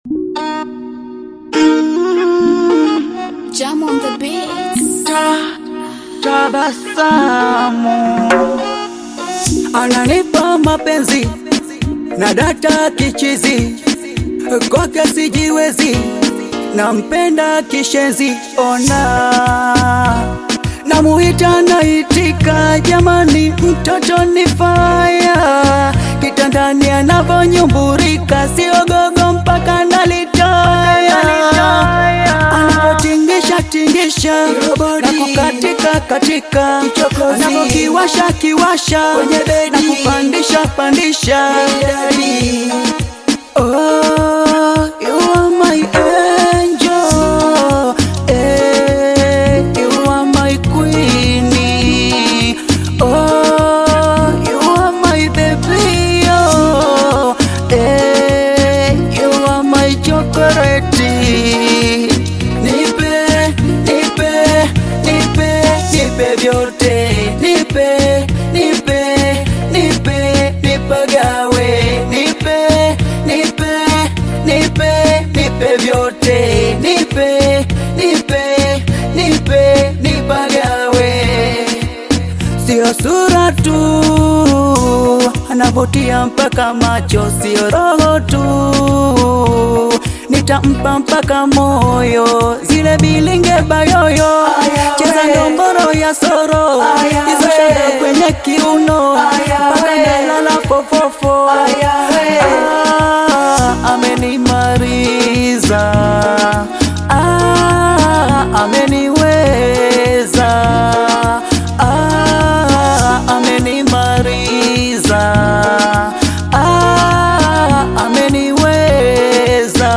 delightful and catchy new tune
singer and songwriter